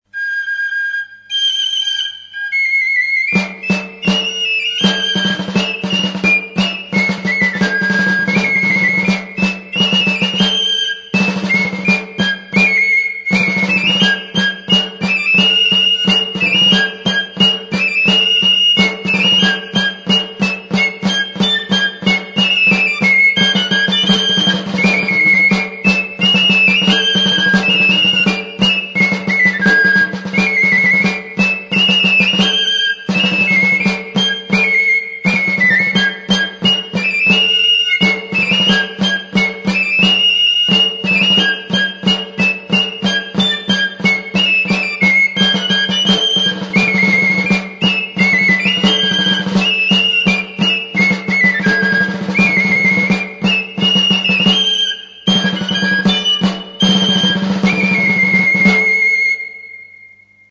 Membranophones -> Beaten -> Stick-beaten drums
TABALA; ATABALA
Zuberoan txirularekin taldea osatzen duen atabala.